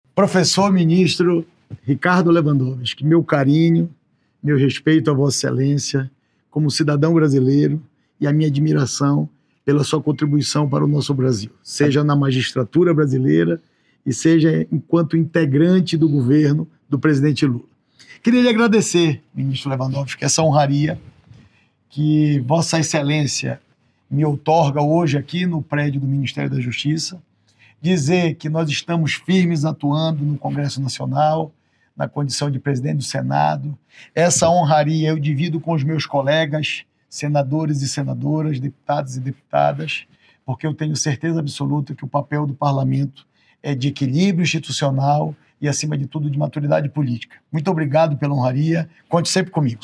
Davi Alcolumbre, presidente do Senado Federal, agradece o recebimento da medalha de Ordem do Mérito pelo MJSP — Ministério da Justiça e Segurança Pública
davi-alcolumbre-presidente-do-senado-federal-agradece-o-recebimento-da-medalha-de-ordem-do-merito-pelo-mjsp.mp3